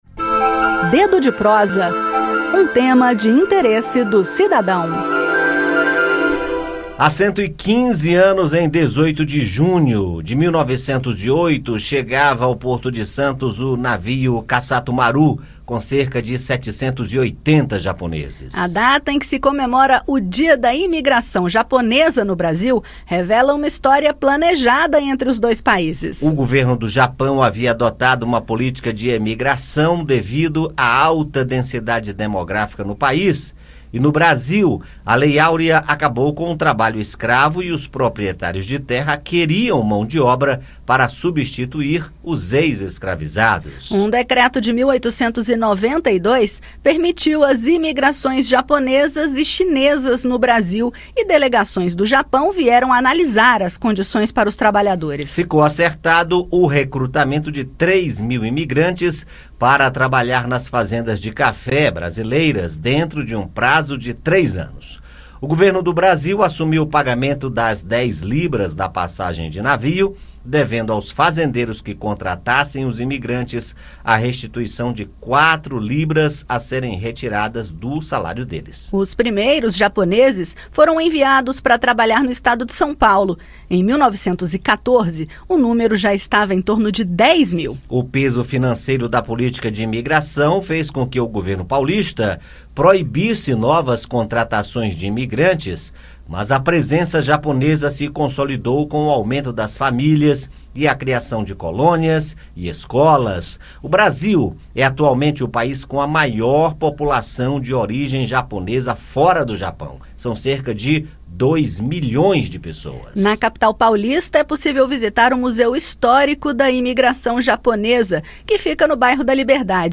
Entre as contribuições da comunidade japonesa para o Brasil está influência nas artes marciais, na espiritualidade e filosofia, na culinária e na agricultura, com desenvolvimento e exportações. No bate-papo, saiba mais sobre esses 115 anos da presença japonesa no país.